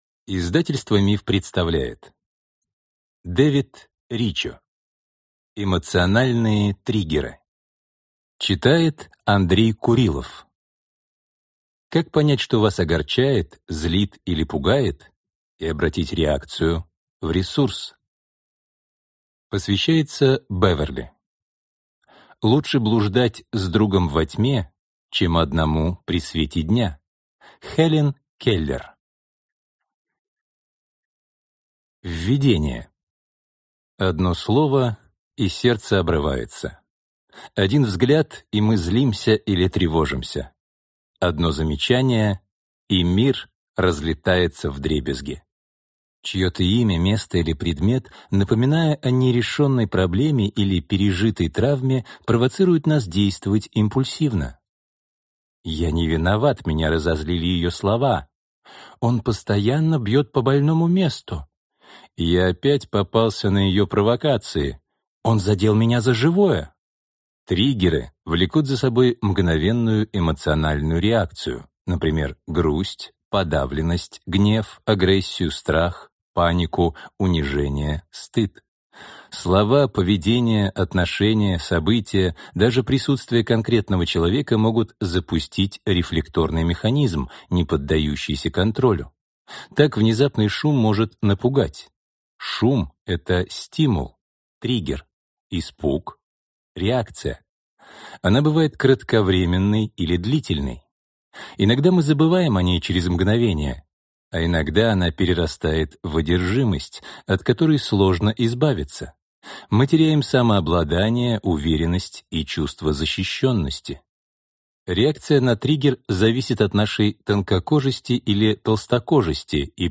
Аудиокнига Эмоциональные триггеры. Как понять, что вас огорчает, злит или пугает, и обратить реакцию в ресурс | Библиотека аудиокниг